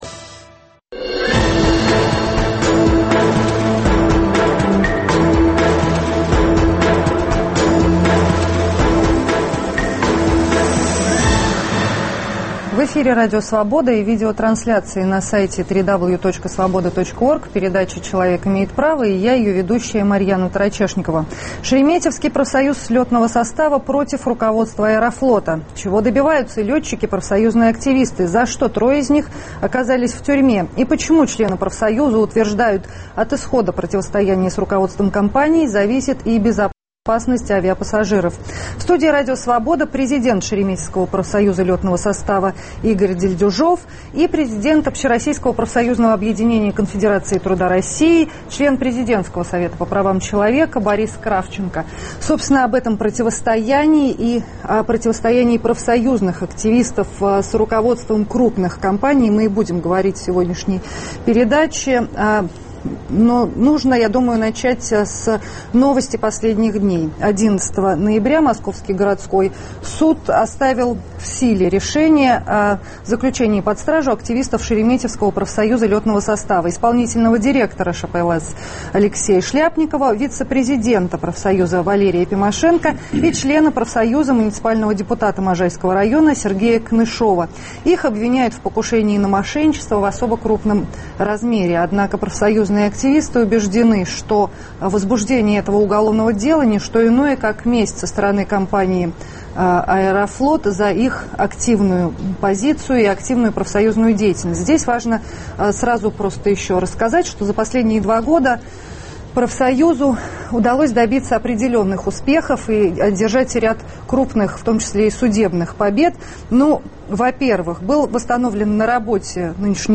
В студии Радио Свобода